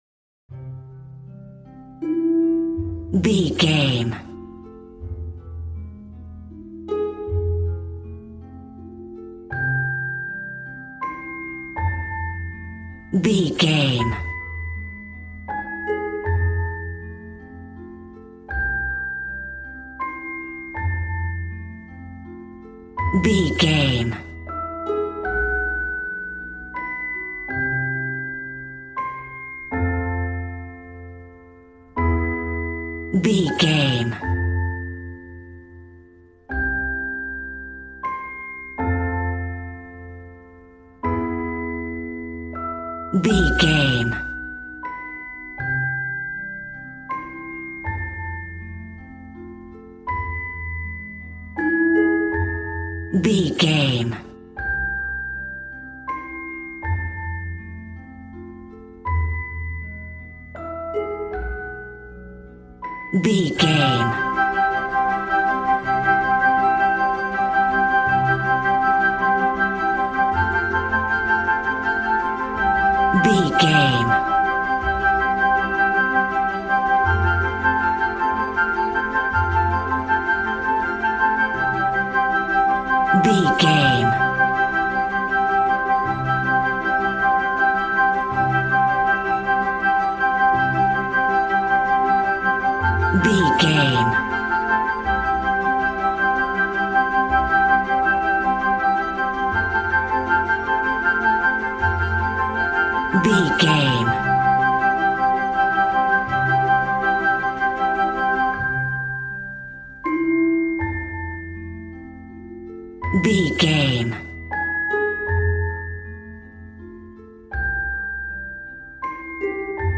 Regal and romantic, a classy piece of classical music.
Aeolian/Minor
G♭
strings
violin
brass